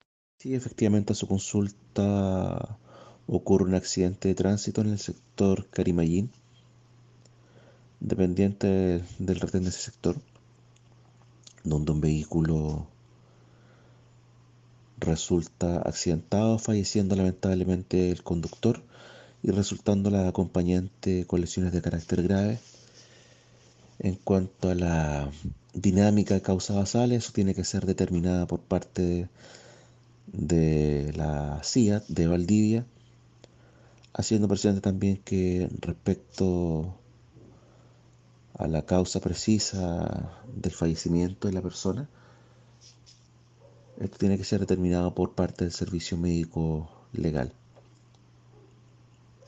fiscal subrogante de Río Bueno, Rodrigo San Martín.